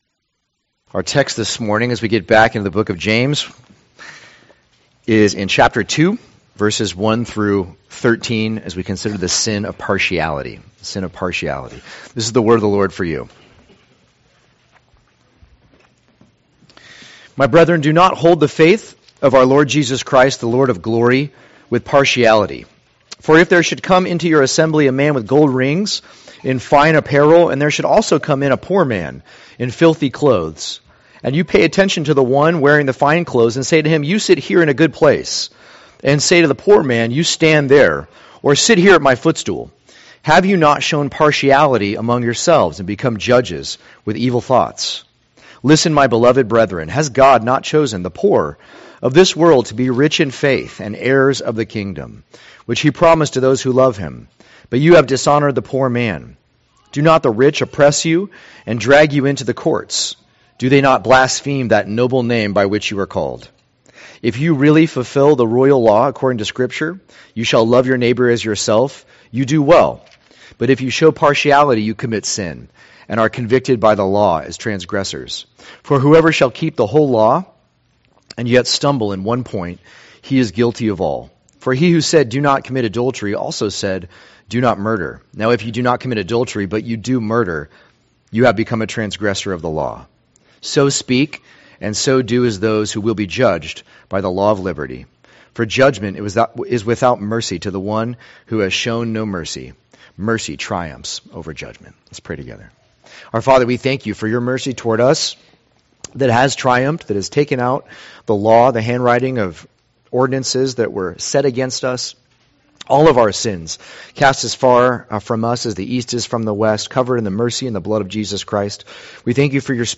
2024 The Sin of Partiality Preacher